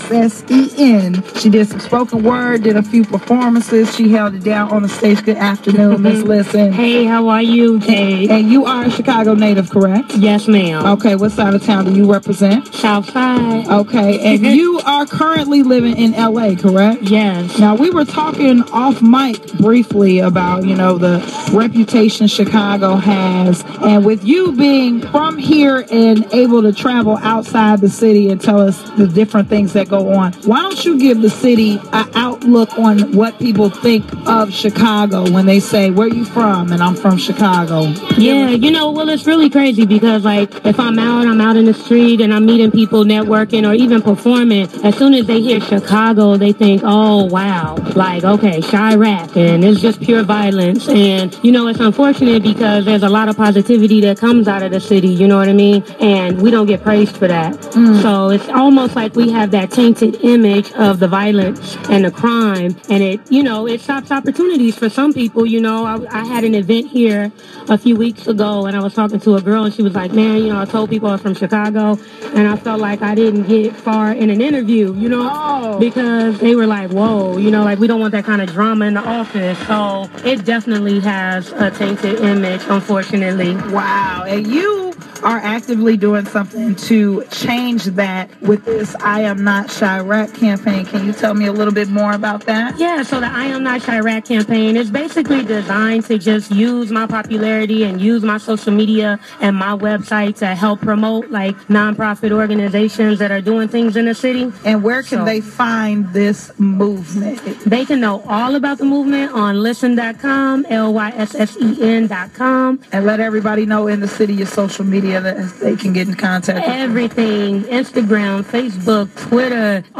POWER 92.3 BMOA BLOCK PARTY (POST INTERVIEW)